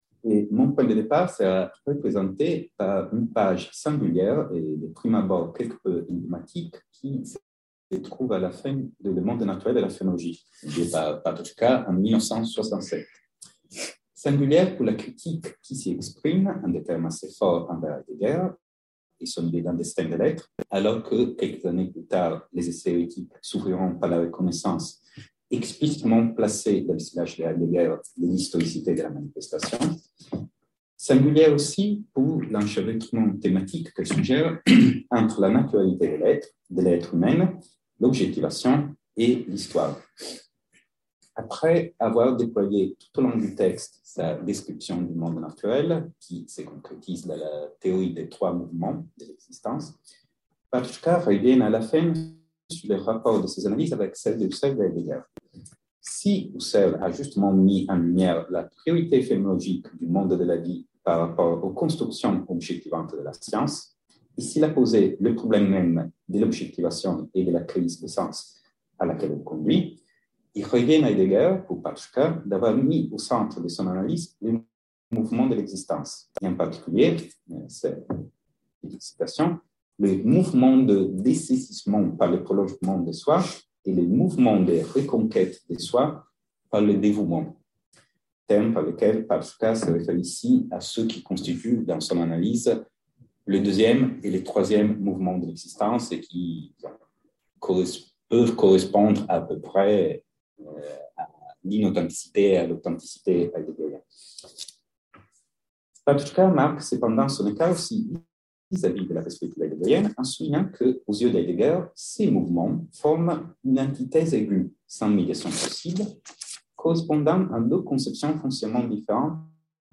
Cette conférence prononcée dans le cadre du colloque sur la pensée de Patočka interroge l’historicité de la vie humaine en cherchant à mettre en lumière le rapport entre la vie biologique et la vie historique. Il montre ainsi que selon Patočka toute vie historique se trouve menacée par une rechute dans ce qui est simplement « le vital ».